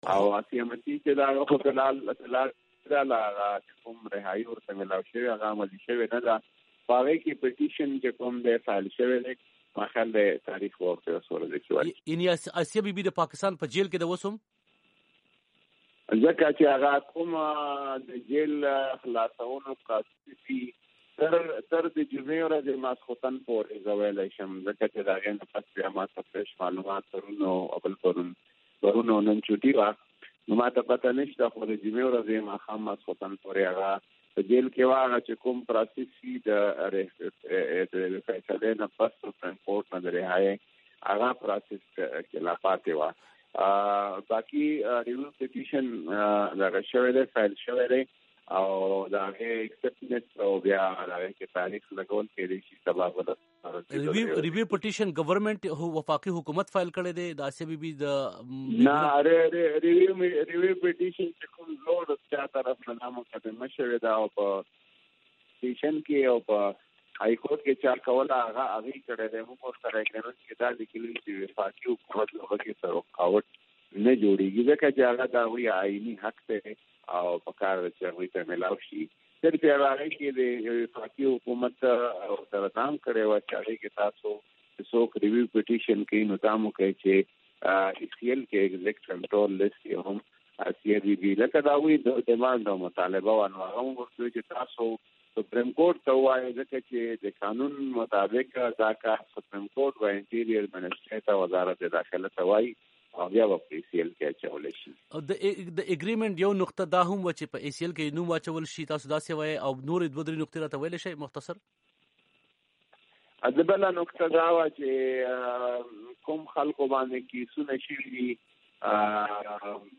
ښاغلي قادري وي او ای ډیوه ته په یوه ځانگړیښې مرکه کې وویل:
د ښاغلي نورالحق قادري مرکه